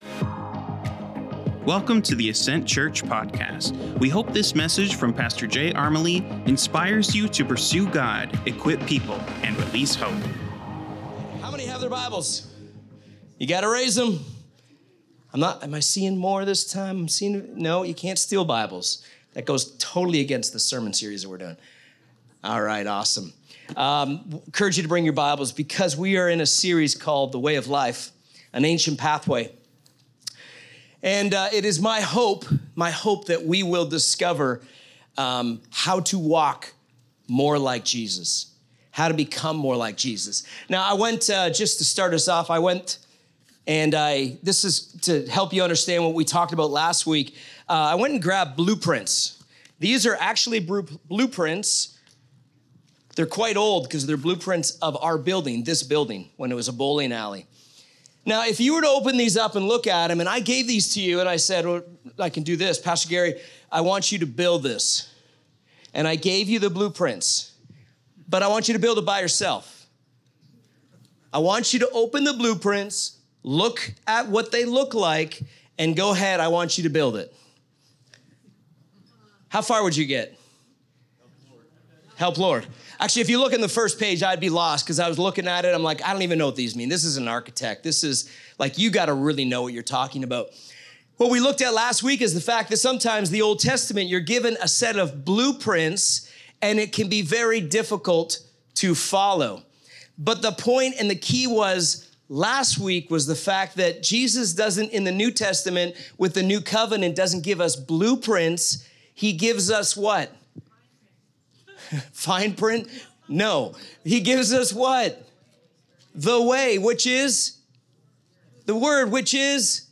Current Weekly Sermon